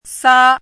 chinese-voice - 汉字语音库